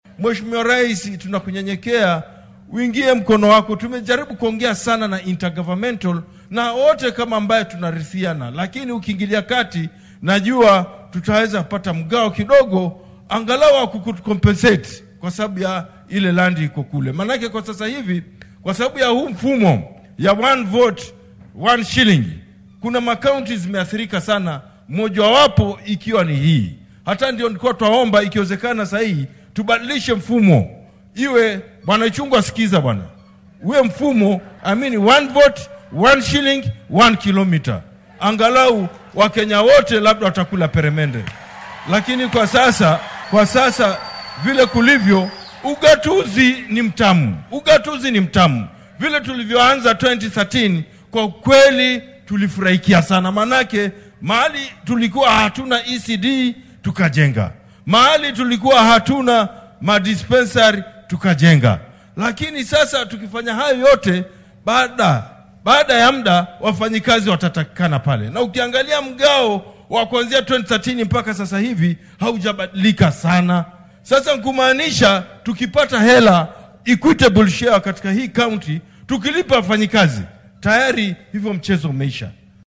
Barasaabka ismaamulkaasi,Andrew Mwadime oo goobta hadal kooban ka jeediyay ayaa ku taliyay in dakhliga qaran lagu qaybsado baaxadda dhulka ee dowlad deegaan kasta.